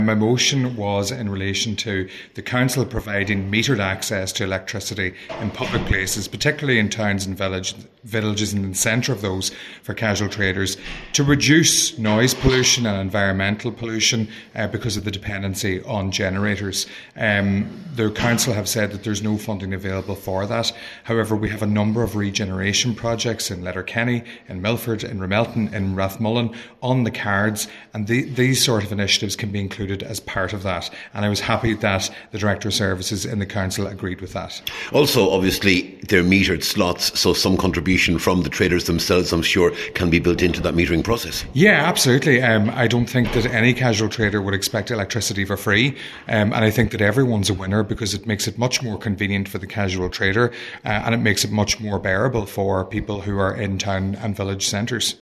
Cllr Meehan says provisions for urban renewal and streetscape improvement could be one way of paying for such a process………….